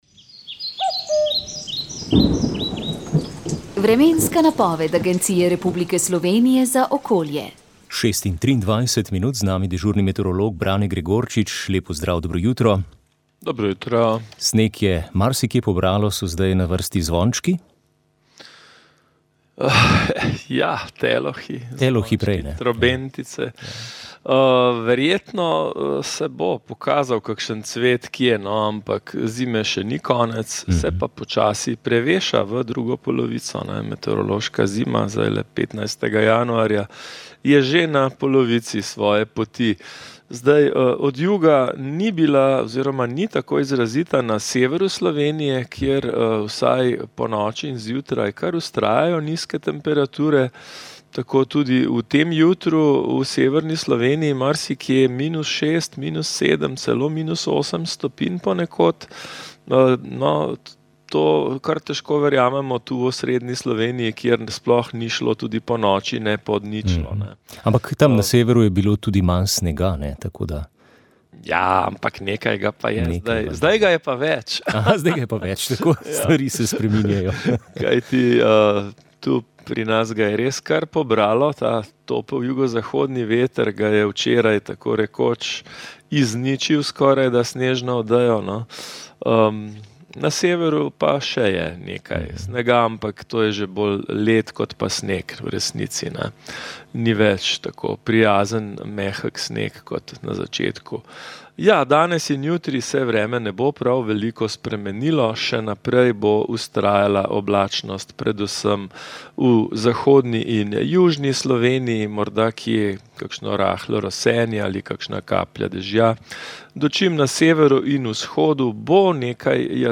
Duhovni nagovor